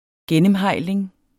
Udtale [ ˈgεnəmˌhɑjˀleŋ ]